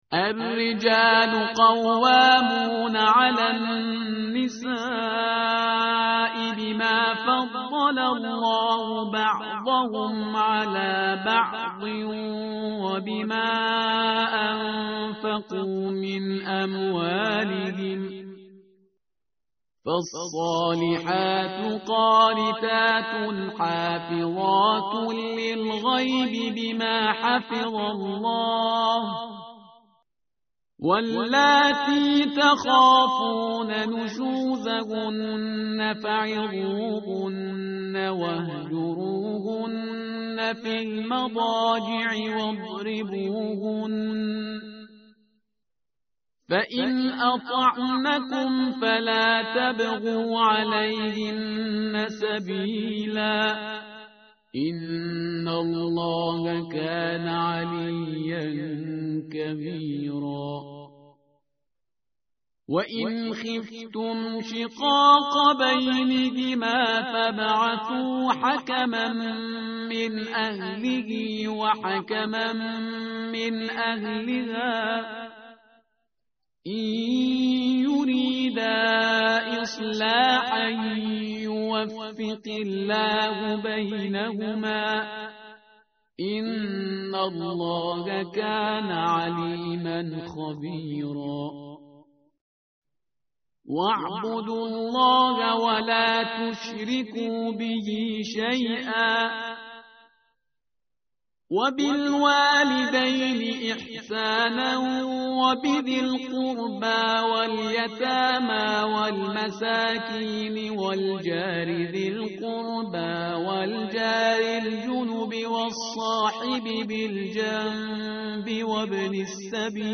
tartil_parhizgar_page_084.mp3